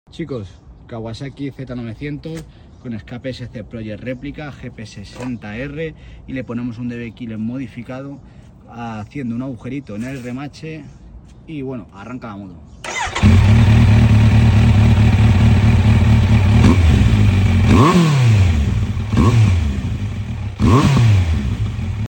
💚 Hoy llegó al garaje una Kawasaki Z900 con escape SC Project réplica.
🔊 Un rugido más gordo 💥 Petardeos que hacen sonreír 😎 Y una Z900 que ahora ruge como se merece.
👇 ⸻ 💚 Today at the garage, we had a Kawasaki Z900 with an SC Project replica exhaust.
🔊 A deeper roar 💥 Pops that make you smile 😎 And a Z900 that finally roars like it should.